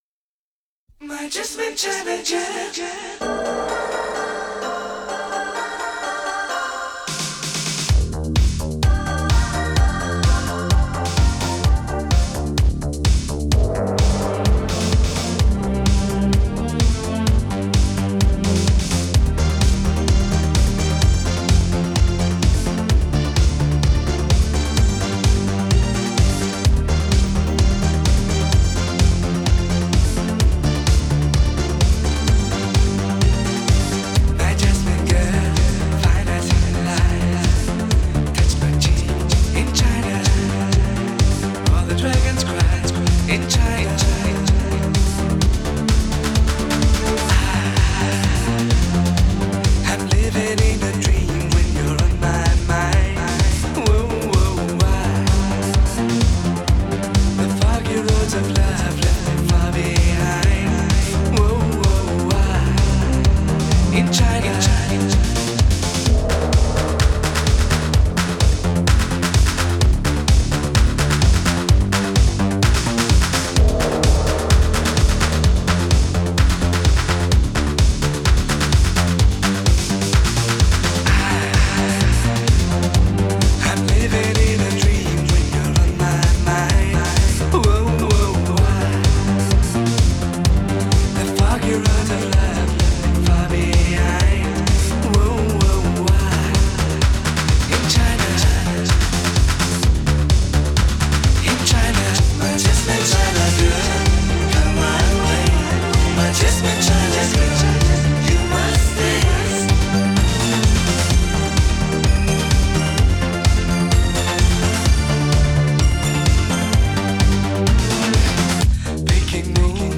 风靡八十年代的舞曲！